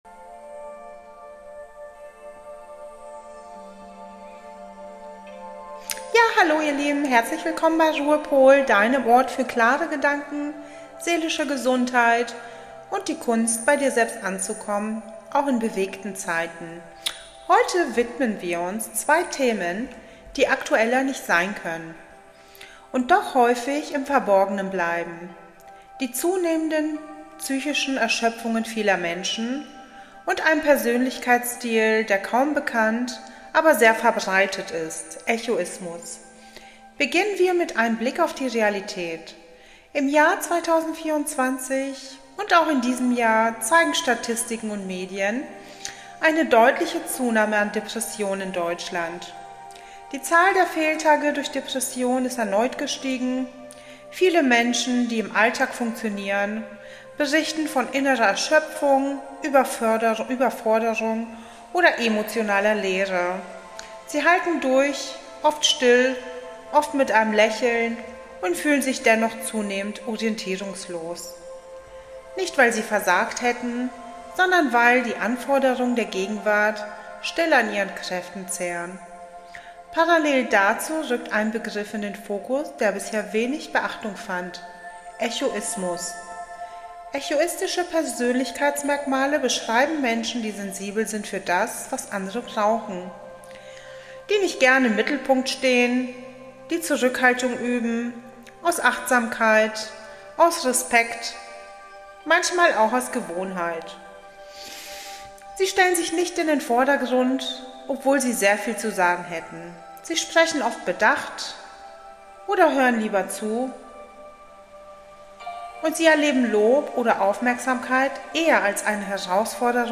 Und zum Schluss eine passende Meditation  Mehr